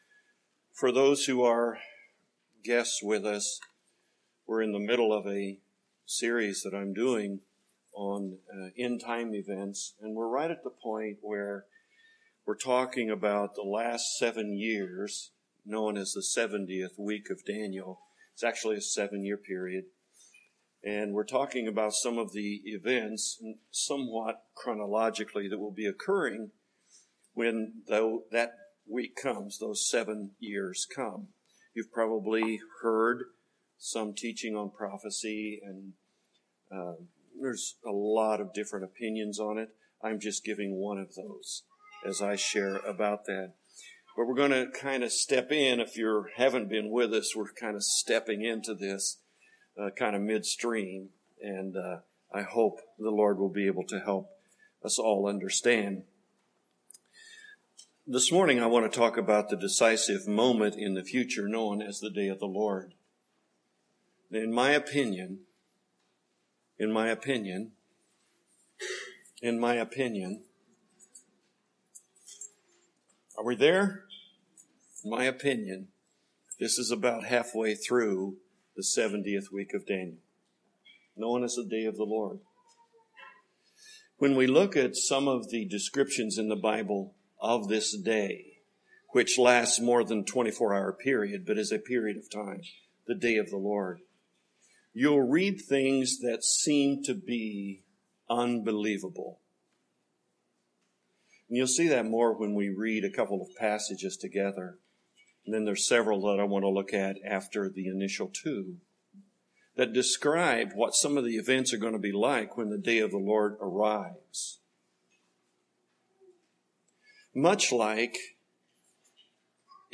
Service Type: Morning Worship Topics: End Times